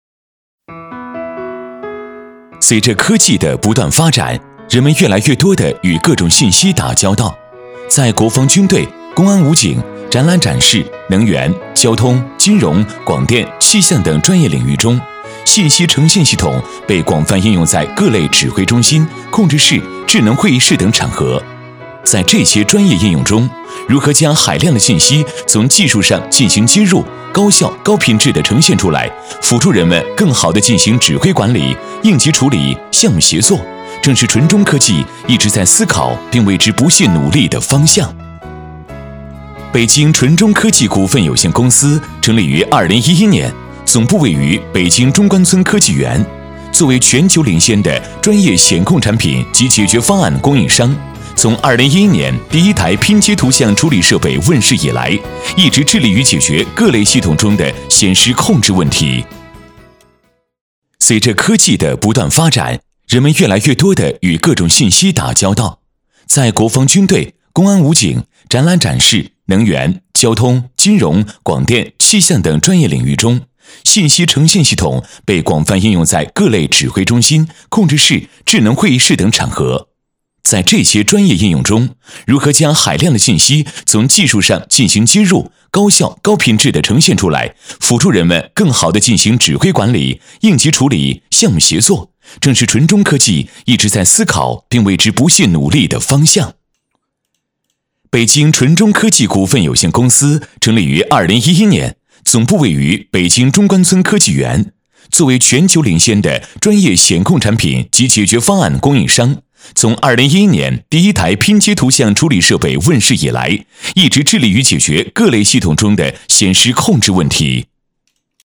男124-说明介绍配音-清澈明快-大连万科gtvk